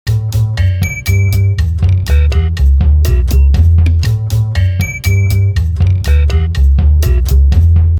this is a polar bear ringtone i made